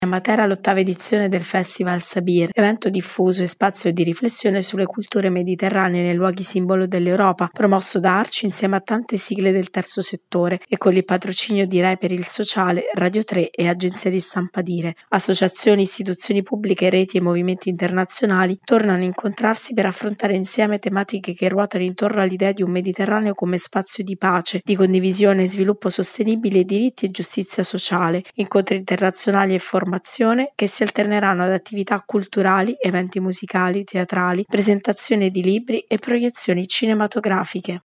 servizio-sabir.mp3